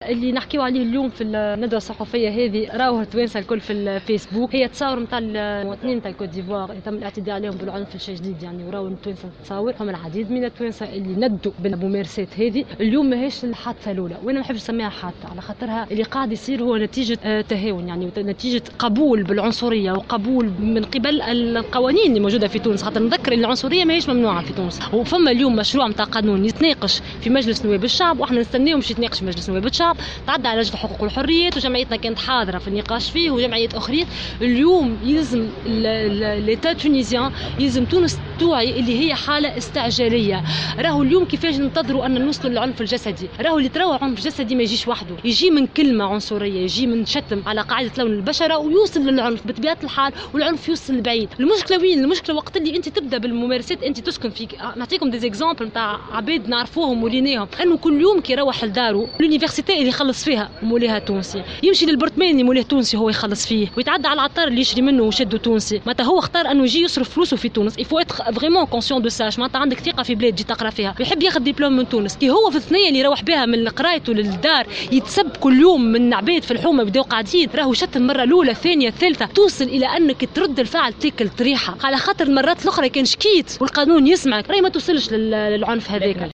طالبت الجمعية التونسية لمساندة الأقليات، اليوم الأربعاء، بالتعجيل بالمصادقة على مشروع القانون الأساسي المتعلق بالقضاء على جميع أشكال التمييز العنصري لوضع حد للاعتداءات المبنية على التمييز العنصري بتونس، وذلك خلال ندوة صحفية عقدتها الجمعية التونسية لمساندة الأقليات وجمعية الإيفواريين الناشطين بتونس، بمقر النقابة الوطنية للصحفيين التونسيين.